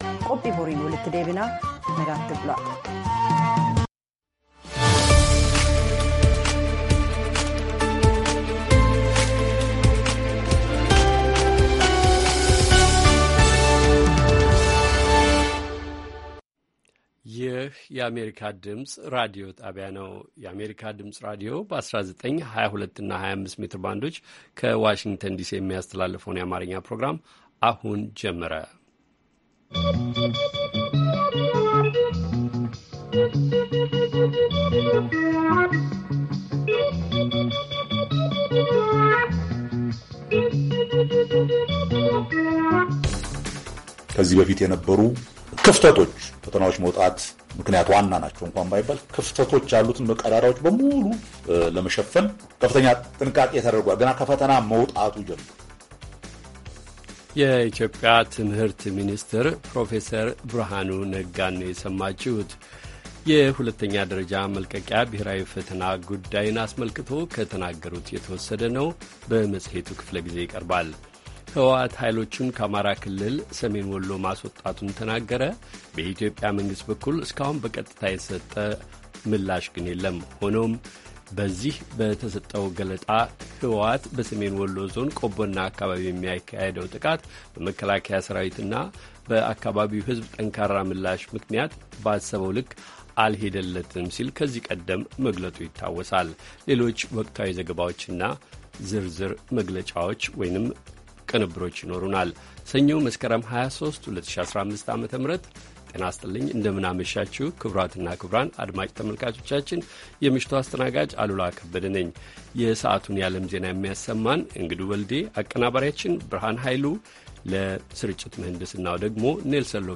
ሰኞ፡-ከምሽቱ ሦስት ሰዓት የአማርኛ ዜና